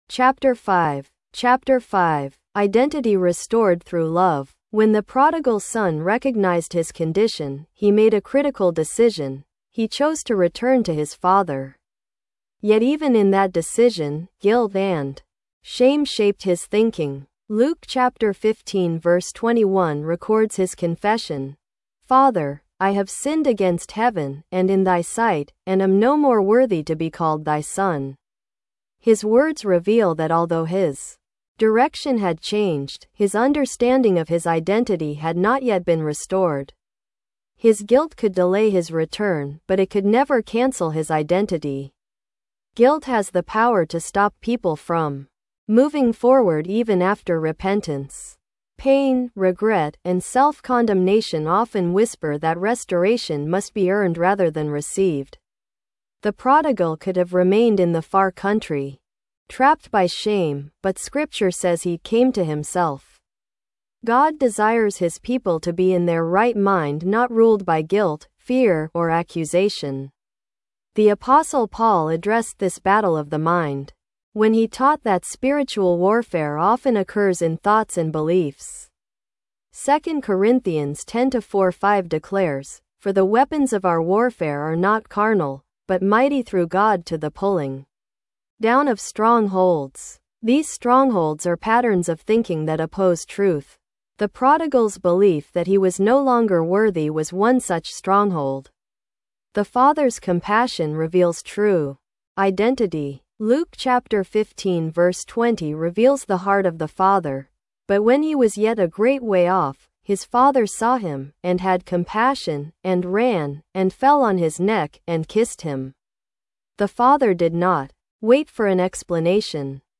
Identity & Purpose: Transforming Living Through IP Audiobook